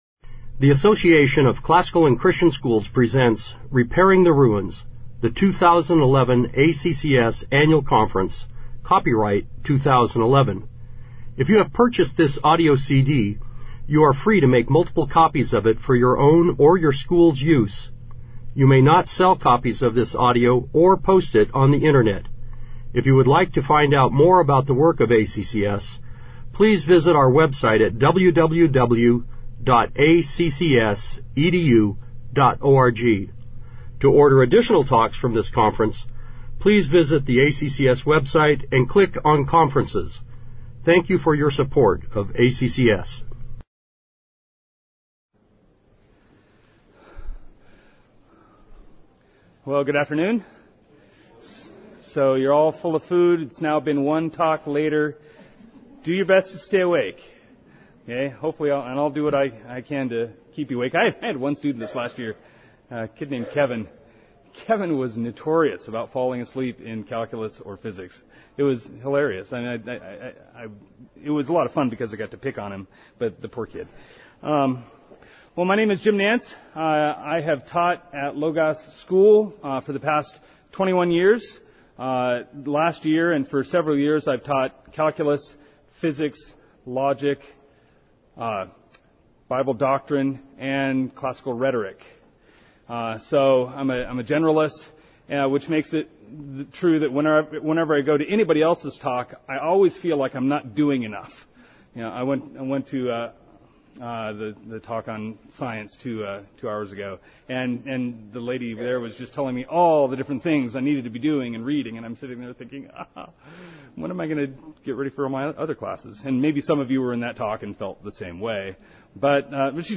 2011 Workshop Talk | 0:56:02 | All Grade Levels, Math
Speaker Additional Materials The Association of Classical & Christian Schools presents Repairing the Ruins, the ACCS annual conference, copyright ACCS.